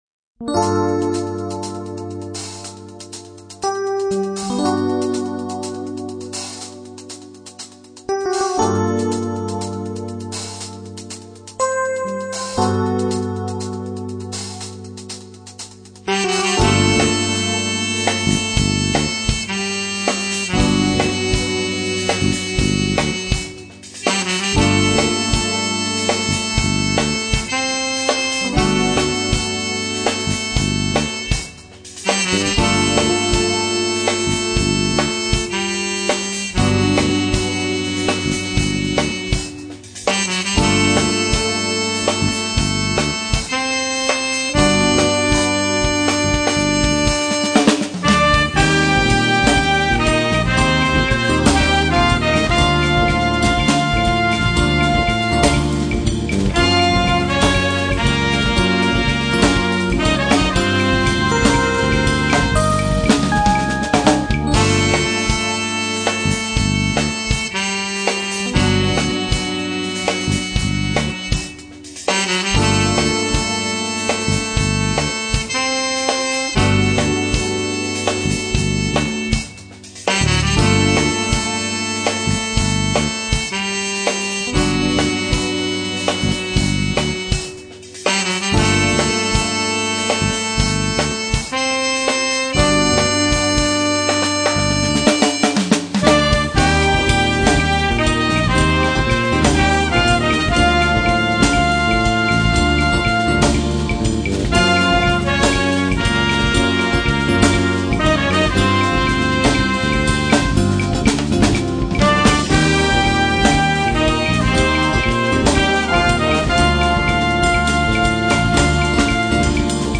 Signál  šel z basy do jakéhosi lampového preampu a pak do linky.